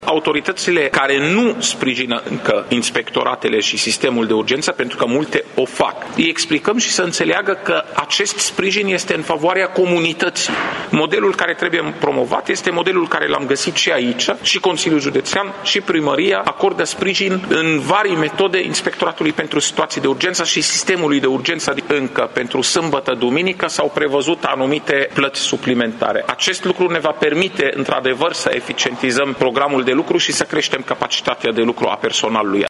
Acest model trebuie preluat și de alte localități, spune secretarul de stat Raed Arafat, prezent azi la evaluarea activității ISU Mureş.
De asemenea, pompierii vor avea anul acesta un program mai bun de lucru, iar orele restante vor fi plătite, a precizat Raed Arafat: